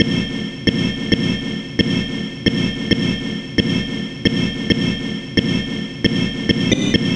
Stab 134-BPM F.wav